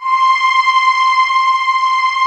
Index of /90_sSampleCDs/USB Soundscan vol.28 - Choir Acoustic & Synth [AKAI] 1CD/Partition D/18-HOLD VOXS